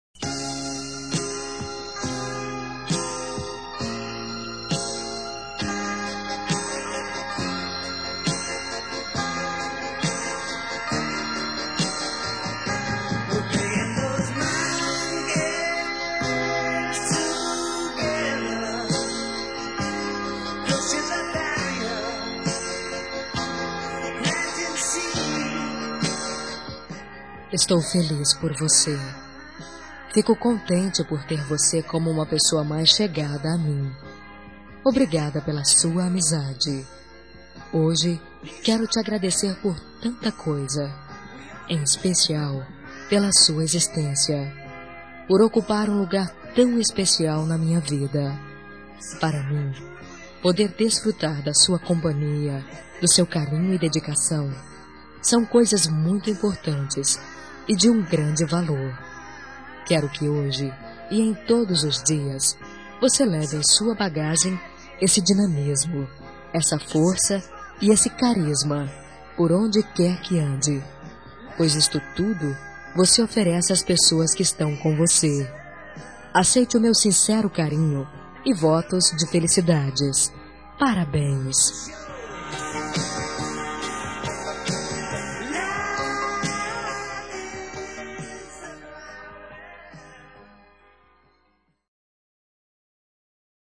Telemensagem de Aniversário de Amigo – Voz Feminina – Cód: 1562